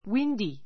windy A2 wíndi ウィ ンディ 形容詞 比較級 windier wíndiə r ウィ ンディア 最上級 windiest wíndiist ウィ ンディエ スト 風の吹 ふ く, 風の強い ⦣ wind （風）＋-y. It was windy all day yesterday.